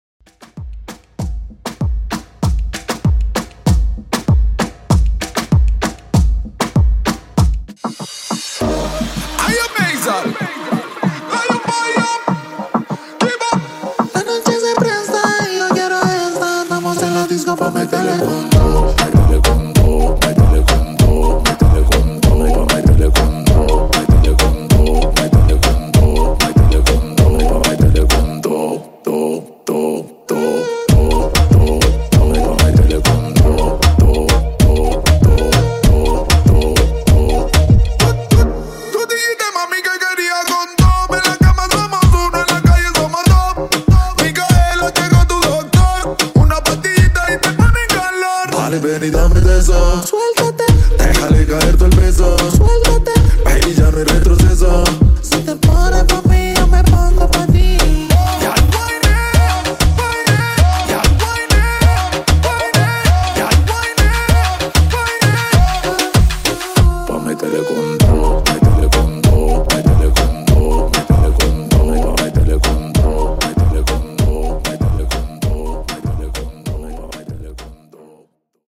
In-Outro Edit)Date Added